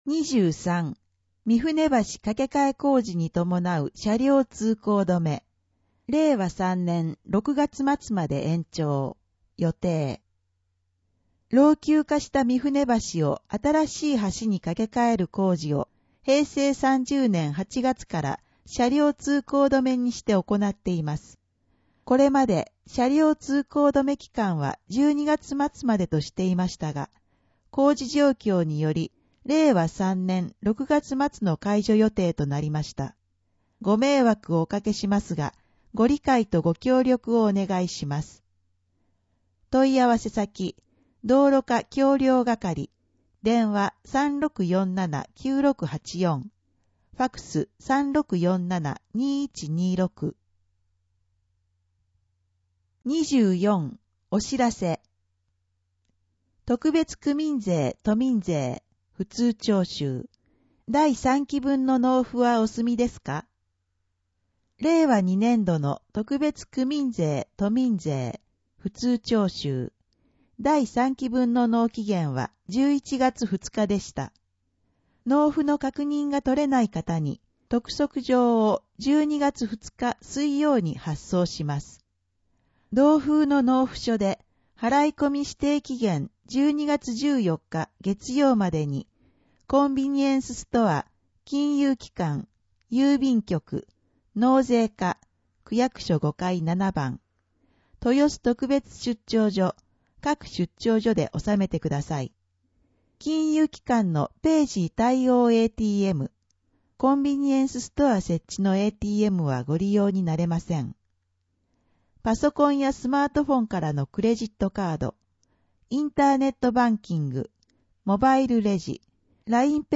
声の広報 令和2年12月1日号（1-8面）